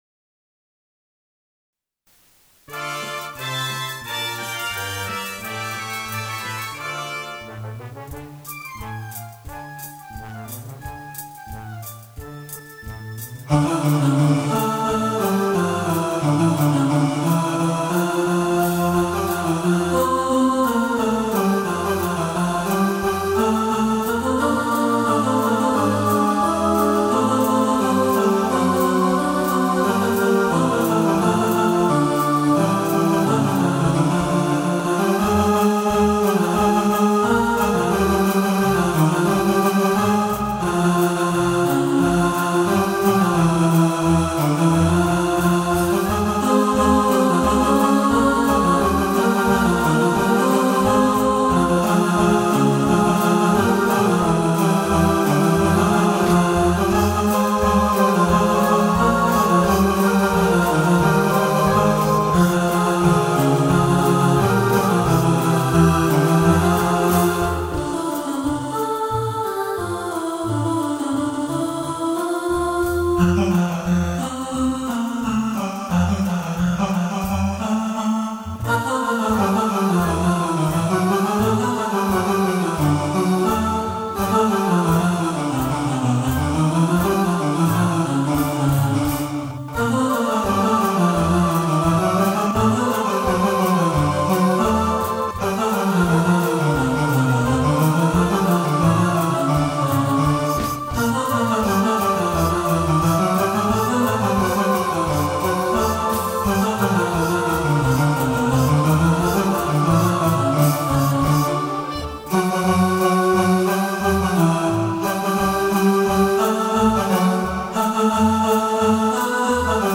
Rhythm Of Life – All Voices | Ipswich Hospital Community Choir